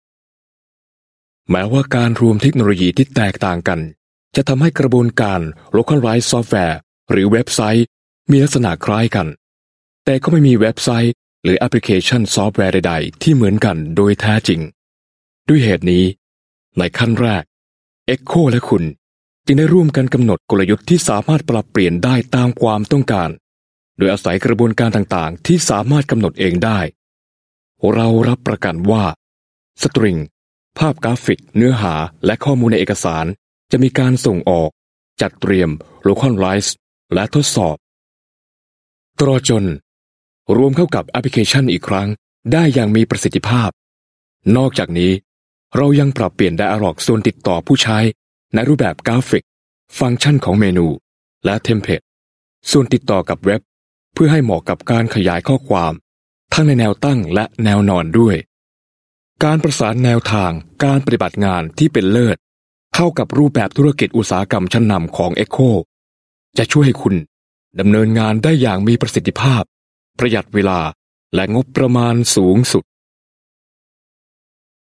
Voice Sample: Narration Sample
We use Neumann microphones, Apogee preamps and ProTools HD digital audio workstations for a warm, clean signal path.